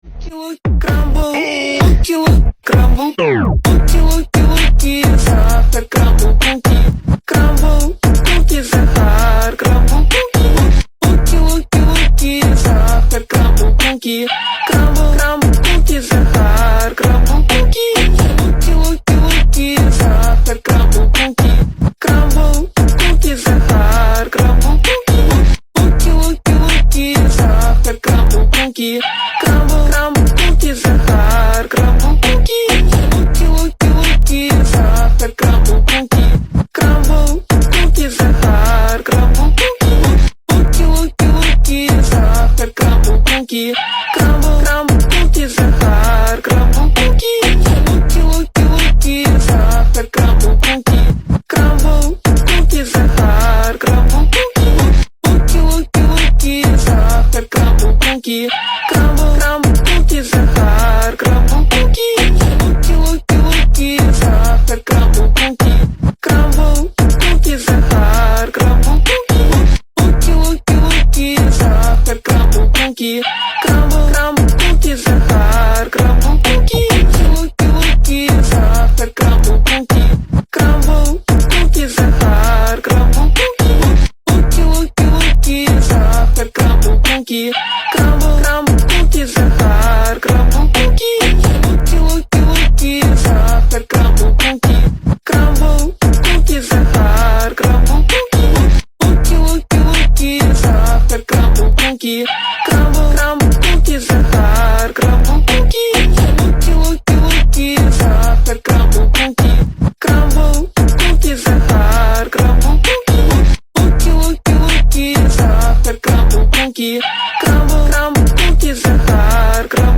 фонк тик ток ремикс тренд 2025